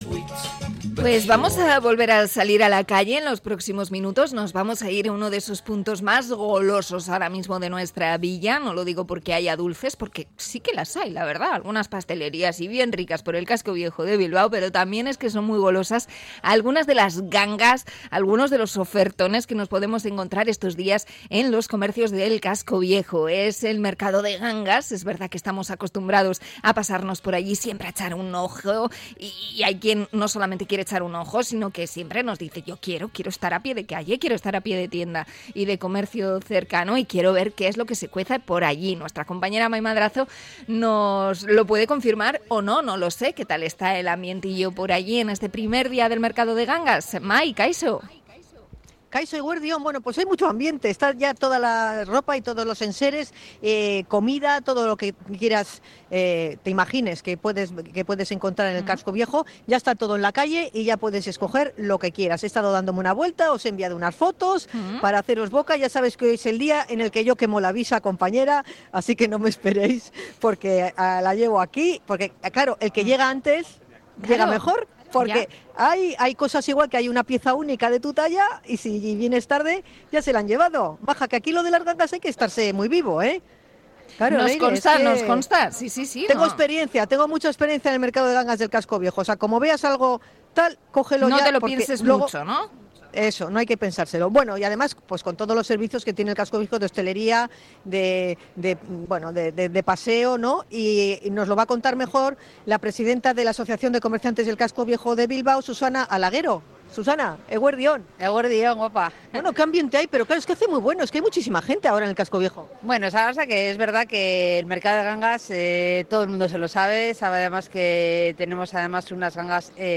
Conexión desde el mercado de gangas del Casco Viejo
Ambiente en el mercado de gangas / Radio Popular de Bilbao